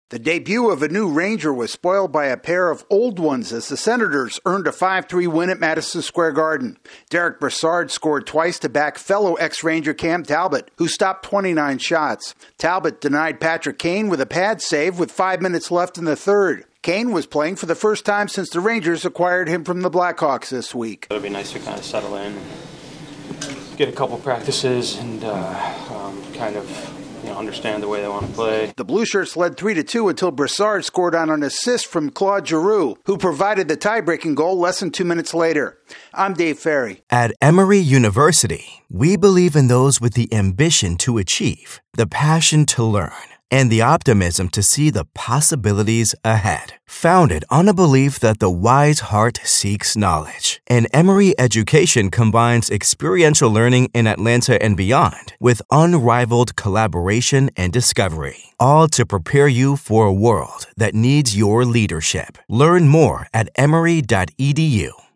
Patrick Kane's first game as a Ranger is a loss to the Senators. AP correspondent